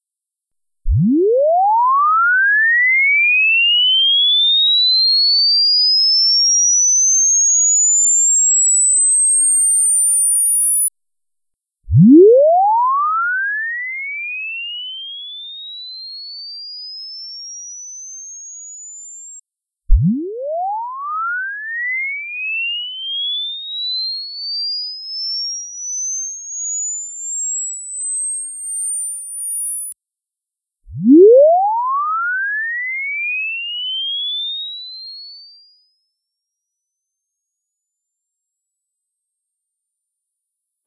What about a sweep whose loudness matches the frequency-analysis …
Or a couple of seconds of noise with the same frequency-analysis …